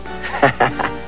8. Laughter is the best medicine.
conlaugh.wav